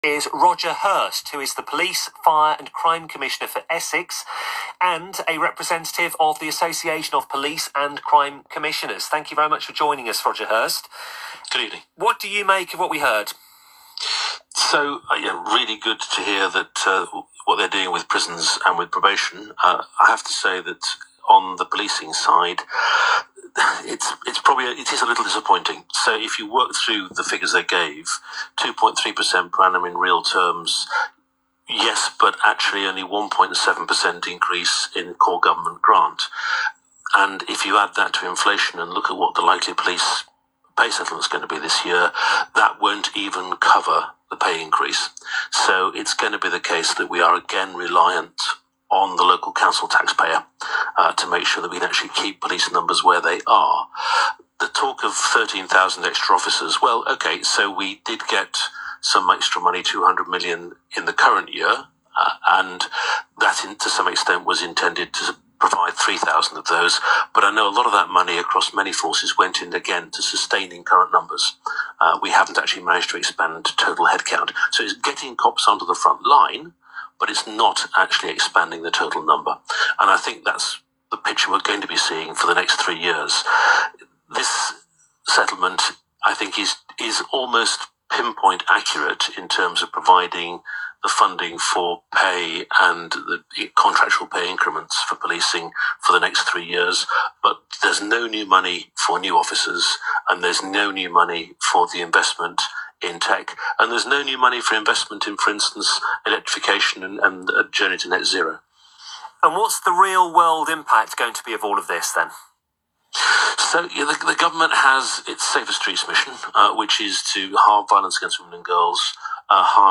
PFCC interviewed on LBC radio about 'disappointing' Comprehensive Spending Review - News - Essex PFCC
Roger Hirst, Police, Fire and Crime Commissioner for Essex, was interviewed on LBC radio this week, giving his view on the Government's Comprehensive Spending Review and what it means for Police and Fire and Rescue nationally.